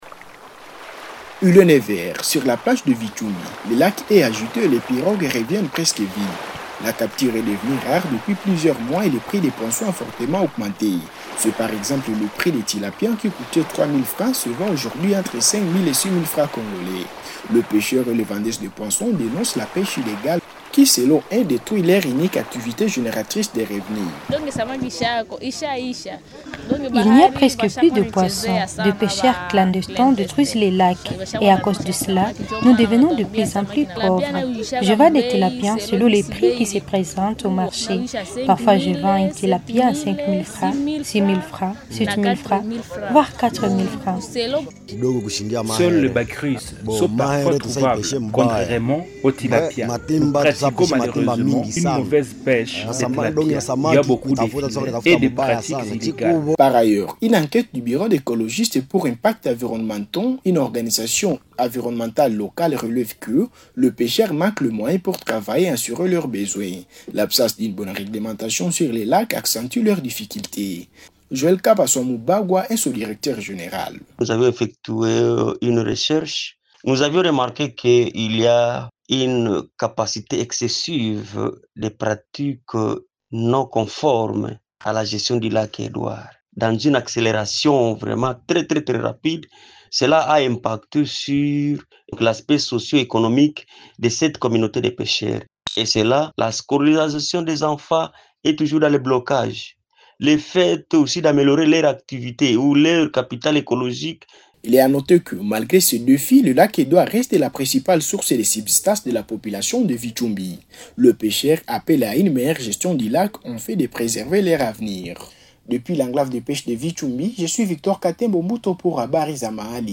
REPORTAGE-VITSHUMBI-LAC-FR_OK_01.mp3